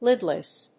アクセント・音節líd・less